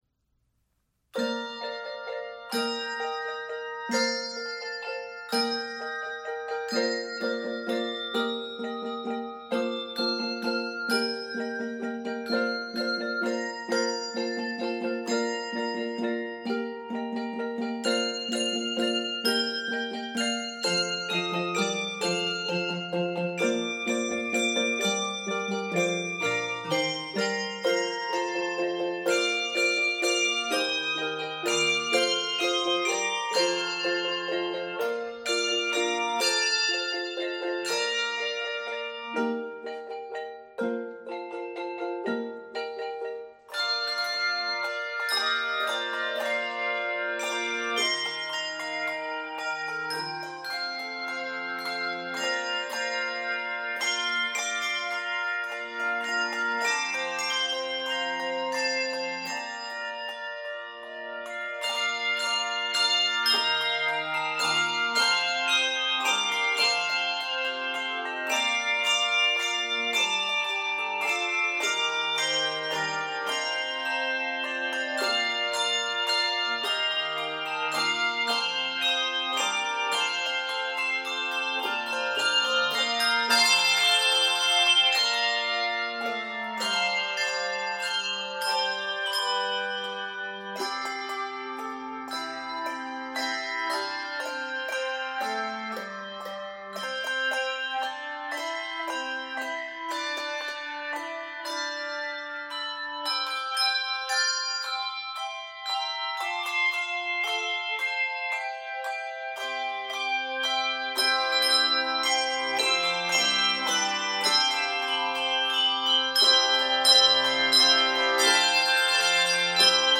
Keys of Bb Major and C Major.